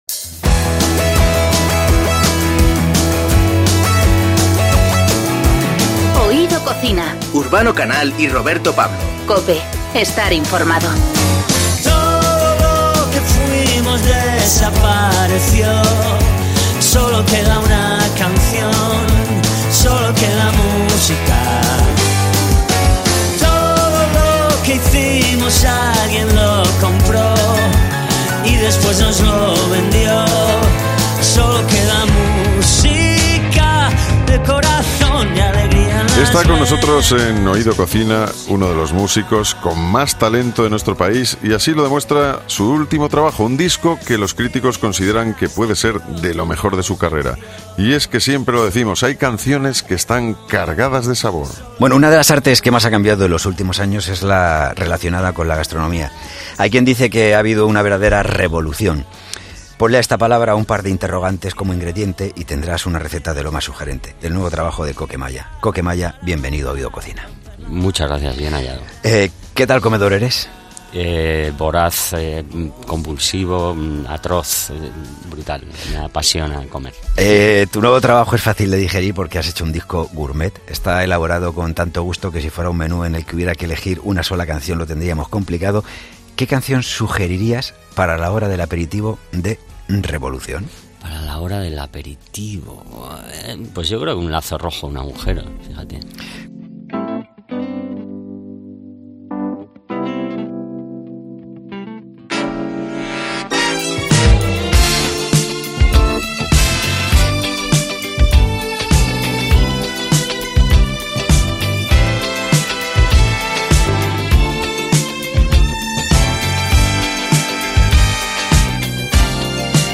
En Oído Cocina hemos charlado con Coque de sus gustos y nos ha contado alguna de las recetas con las que suele agasajar a sus invitados.